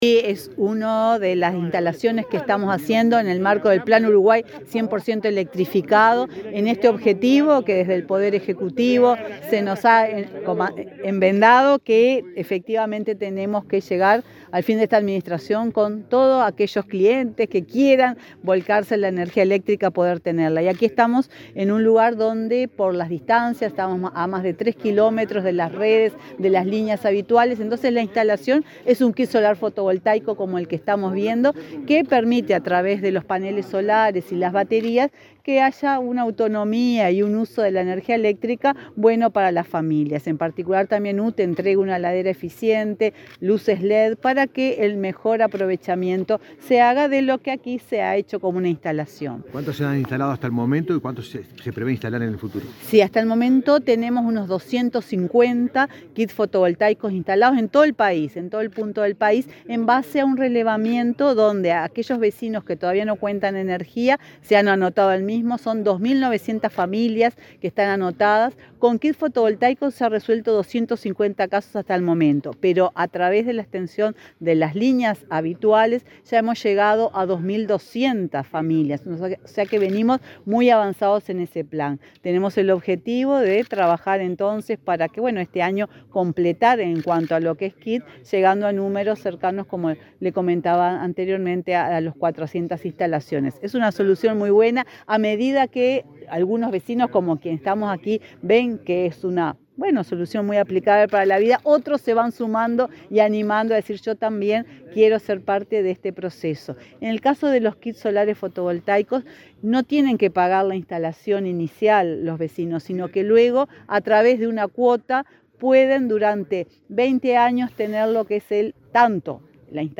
Declaraciones de la presidenta de UTE, Silvia Emaldi
La presidenta de UTE, Silvia Emaldi, dialogó con la prensa sobre los equipos fotovoltaicos entregados hoy en Durazno, en el marco del plan Uruguay 100